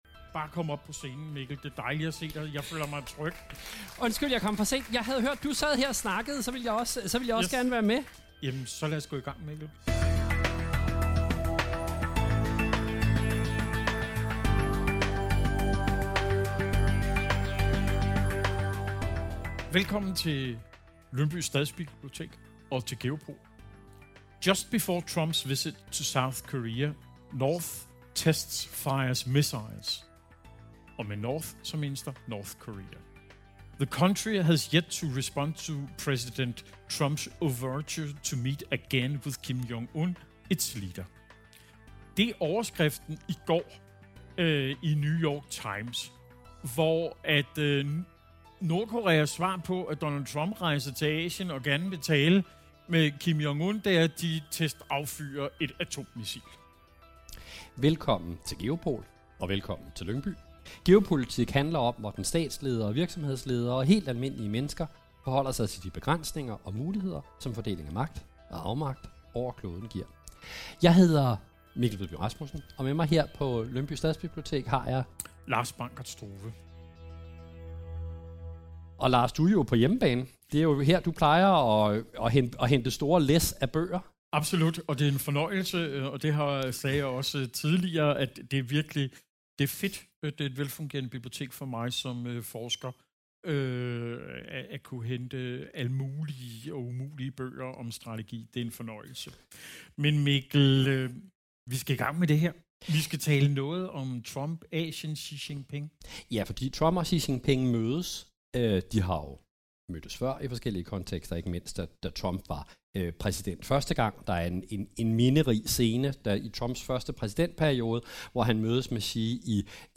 diskuterer armlægningen mellem Trump og Xi live fra Stadsbiblioteket i Lyngby.